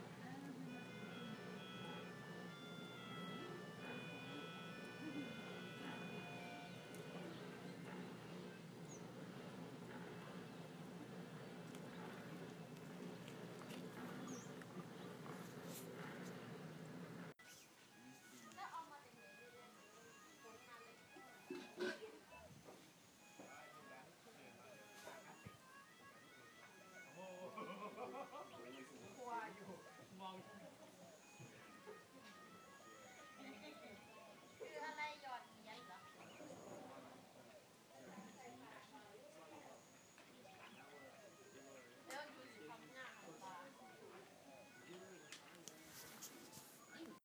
당장 해먹에서 내려와 소리를 물어물어 따라가면 내 방갈로 군락 맞은편 군락에서 들려오는
음정 박자에 조화를 찾을 수 없는 그저 연주자의 마음과 마음만 들어있는 무질서가 나를 행복하게 만드는 이 소리.
멜로디언이라니.
썬 베드에 누워 세월을 결이 없는 음계 속에 흘려보내고 있는 소리의 지배자를 멀리서 훔쳐봤다.